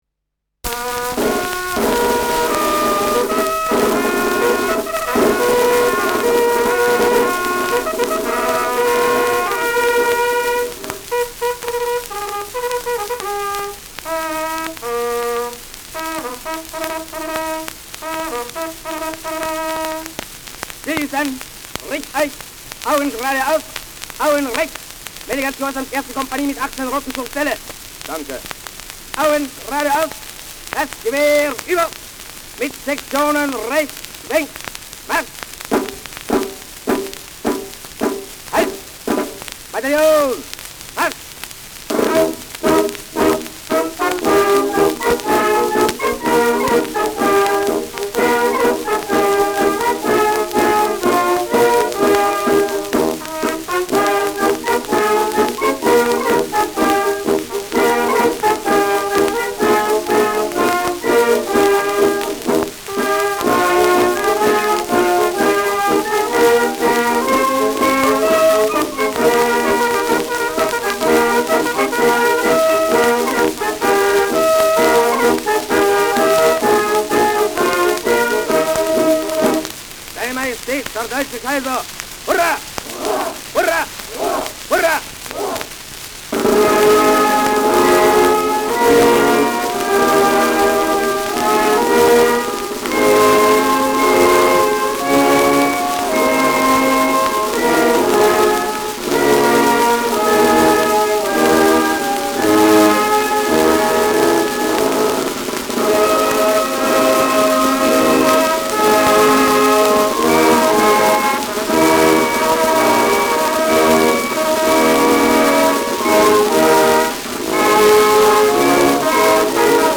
Schellackplatte
Militärische Signale, militärische Kommandos, Marschmusik